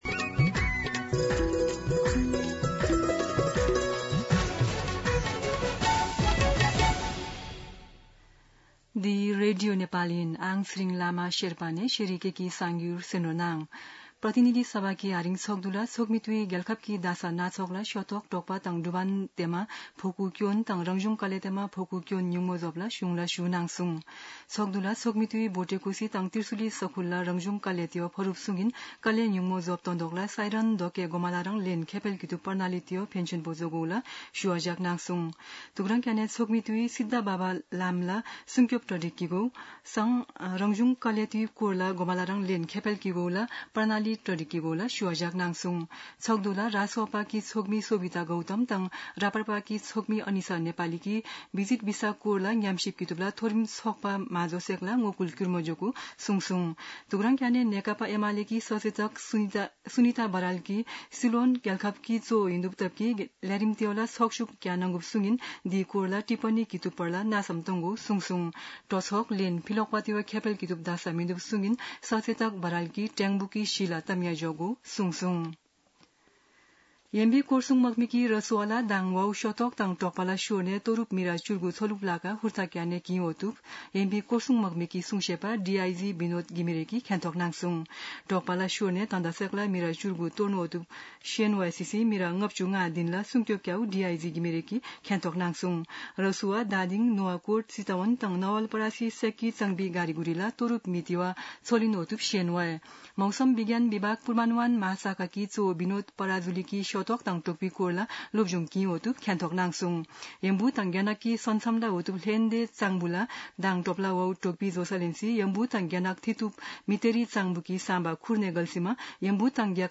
शेर्पा भाषाको समाचार : २५ असार , २०८२